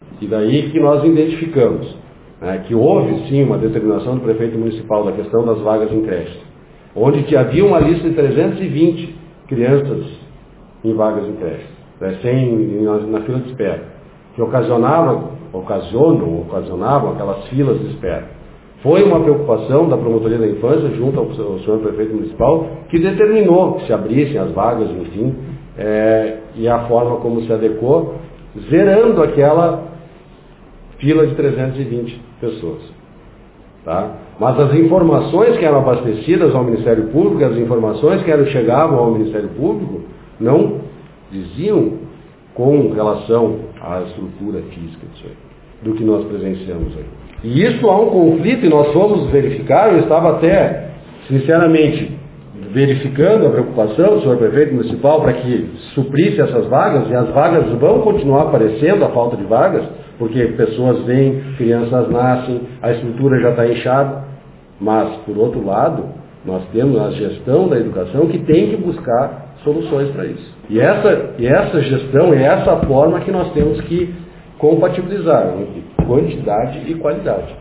Em coletiva de imprensa realizada na manhã de hoje, os promotores João Pedro Togni e Rogério Fava Santos, apresentaram situações precárias de diferentes educandários da cidade.
O promotor Rogério ainda falou sobre as notificações acerca das vagas nas creches e destacou que é necessário compatibilizar quantidade de alunos e qualidade no atendimento dos mesmos: